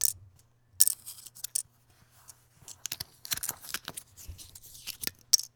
household
Coffee Cup Lid Open Fix